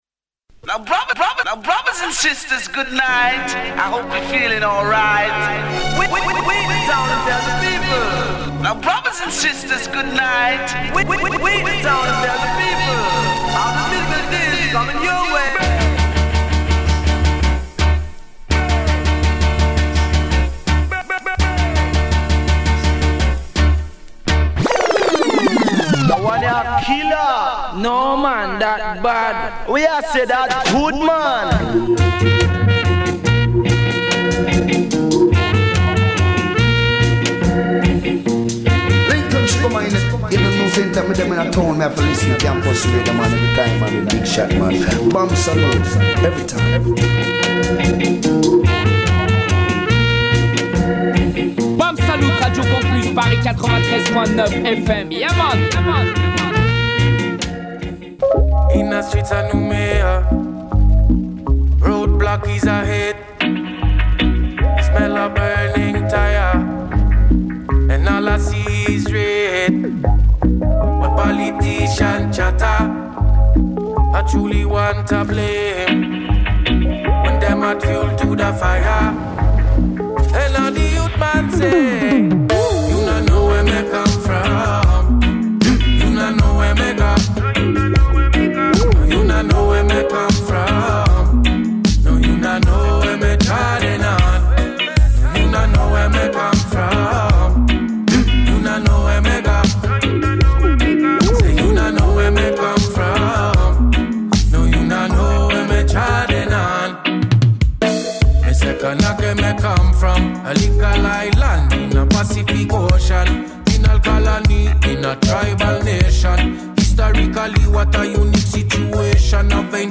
double mix